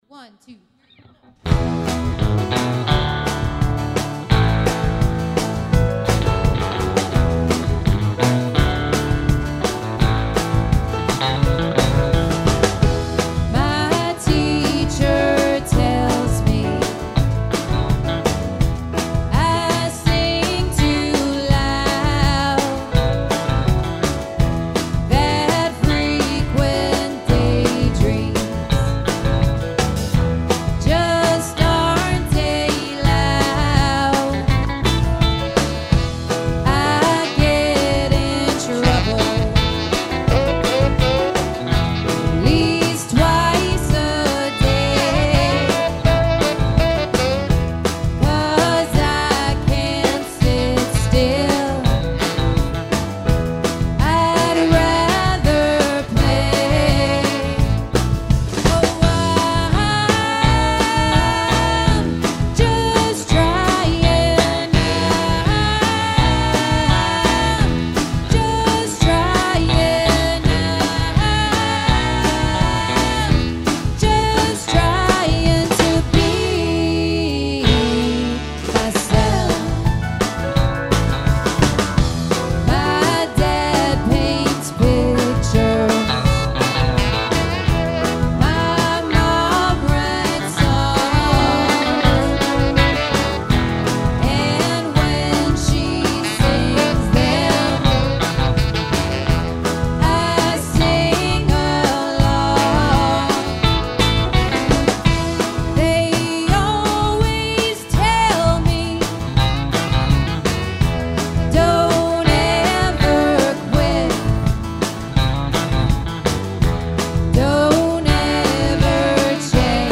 guitar vocals
keys vocals
drums
bass
sax